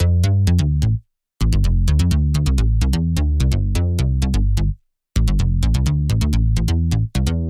描述：128bpm 128bpm
Tag: 128 bpm House Loops Bass Synth Loops 1.26 MB wav Key : Unknown